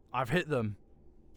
Voice Lines / Combat Dialogue
Marcel Theyre hit.wav